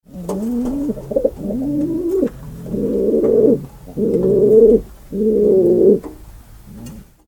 Pigeons Cooing Ambient Sound Effect
Natural pigeons cooing inside a dovecote, creating a calm and authentic ambient sound. This realistic pigeon sound effect captures gentle cooing in a pigeon loft, ideal for nature scenes, rural atmosphere, and background ambience.
Genres: Sound Effects
Pigeons-cooing-ambient-sound-effect.mp3